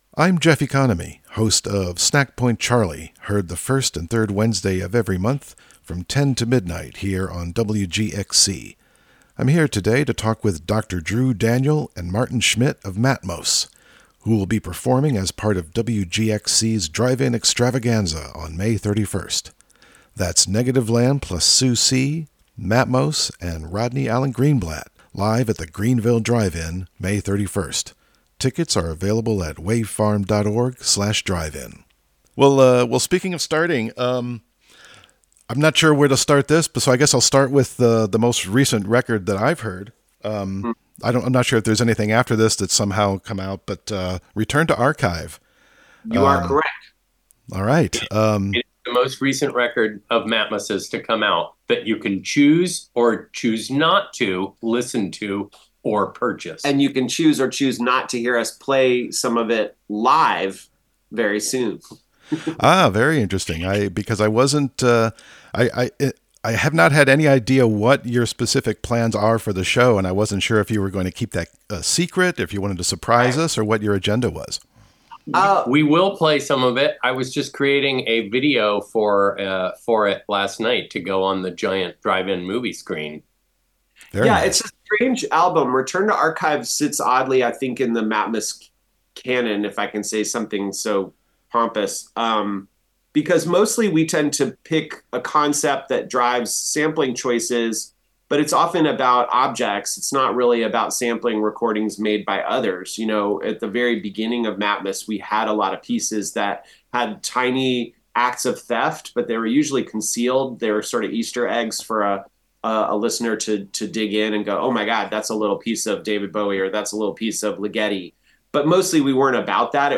Interview with Matmos (Audio)